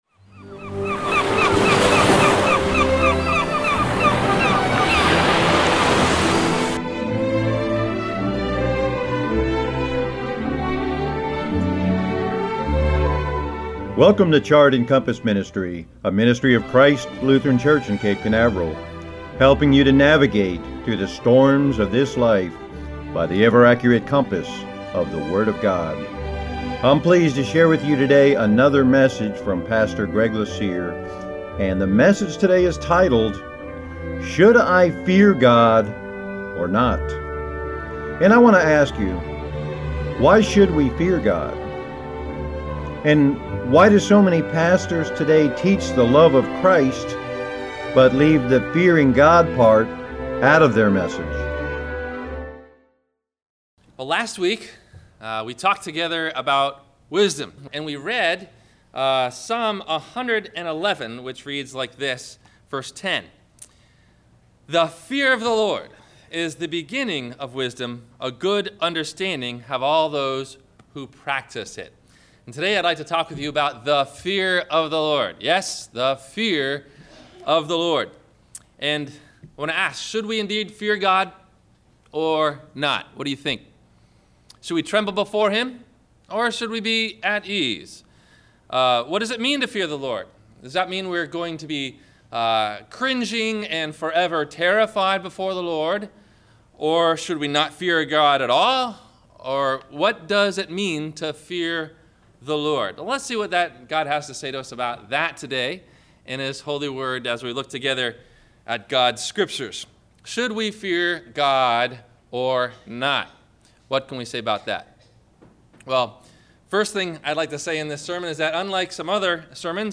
Should I Fear God or Not? – WMIE Radio Sermon – May 04 2015